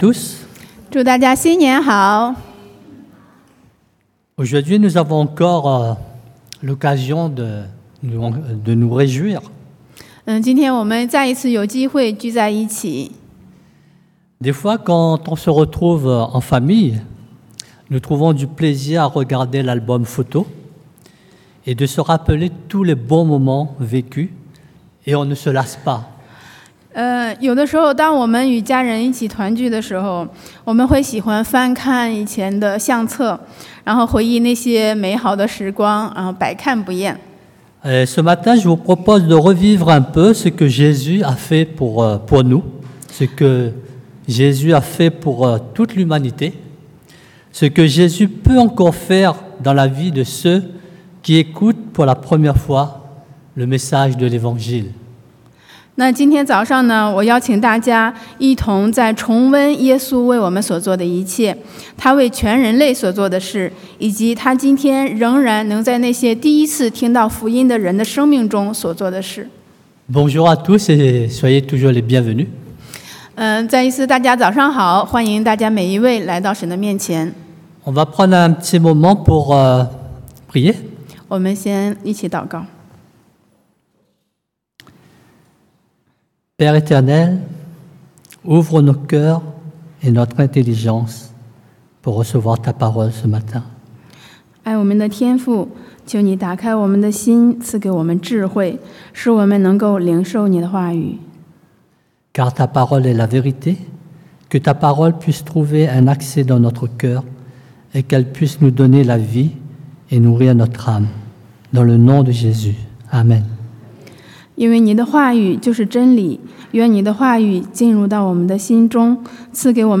Passage: Luc 路加福音 23 : 32-49 Type De Service: Predication du dimanche